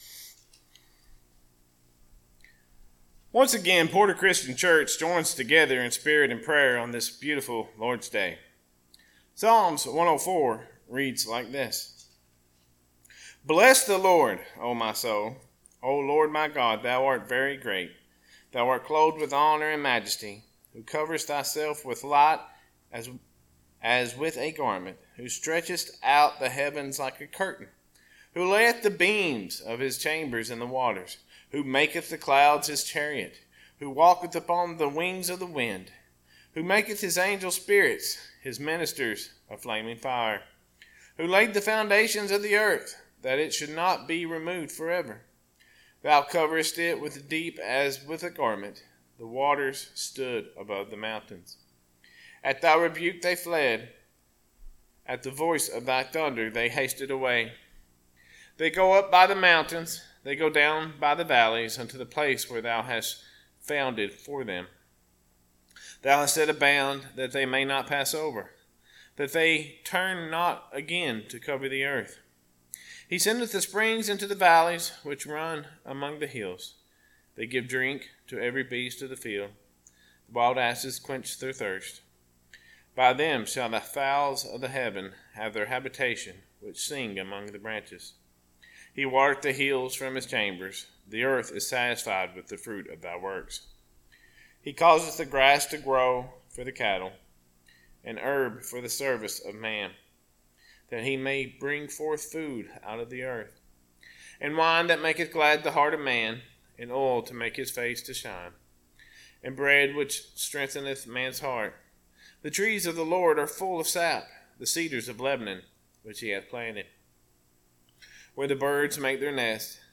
Sermons - Porter Christian Church